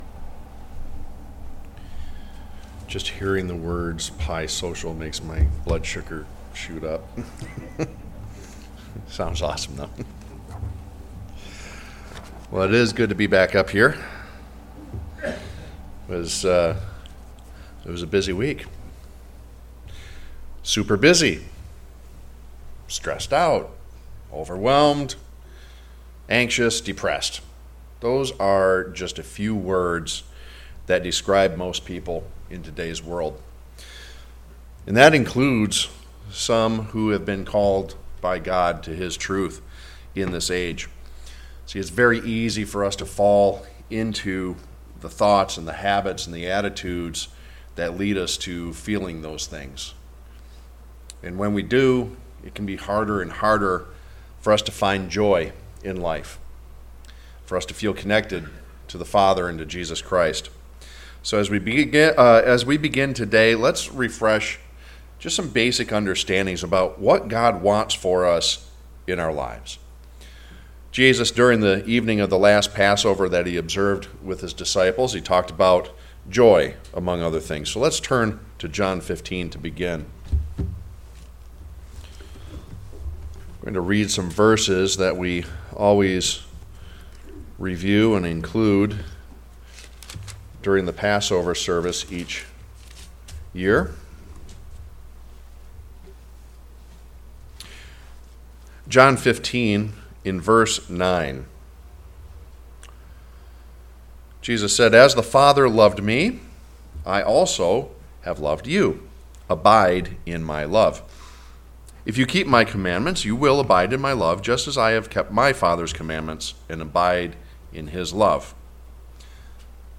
Given in Freeland, MI